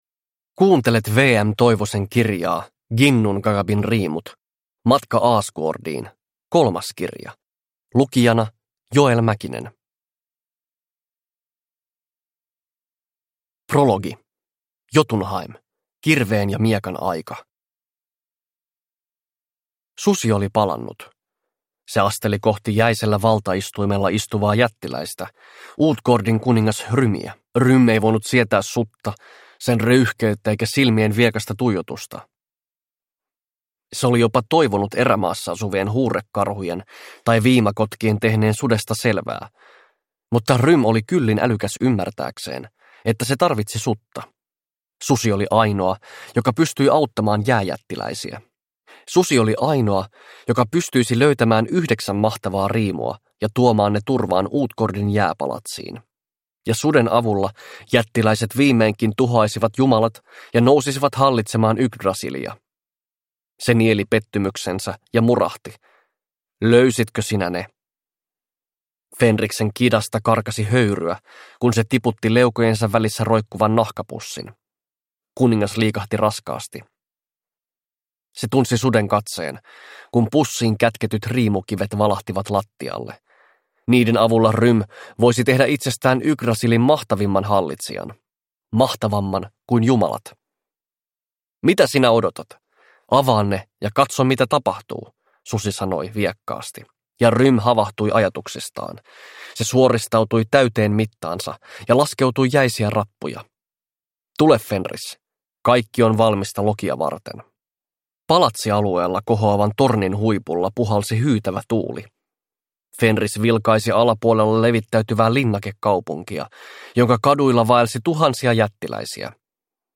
Ginnungagabin riimut – Ljudbok – Laddas ner